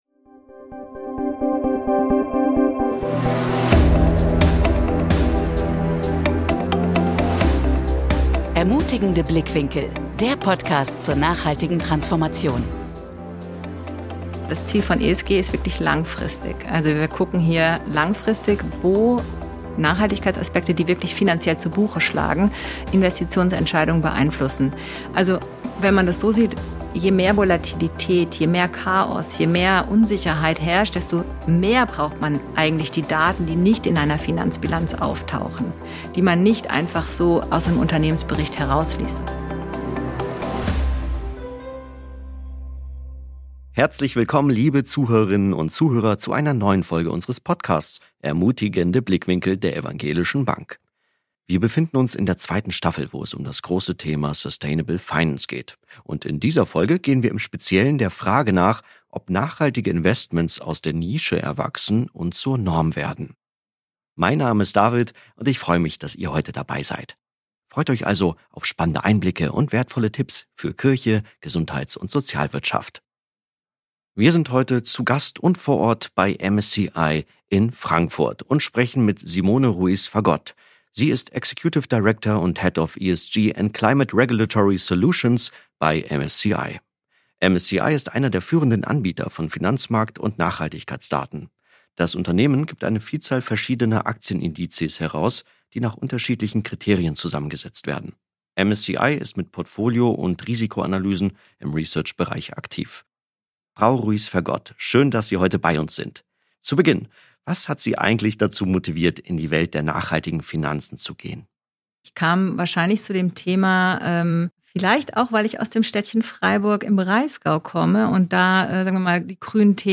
im Gespräch mit unserem Moderator in der zweiten F...